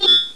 Cipher slash (arcade game)